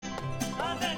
is being transmitted inside a Transport Stream (Digital Television
channel 1, 2, 4, 5 and 6 are always in 0 (silence), and the channel 3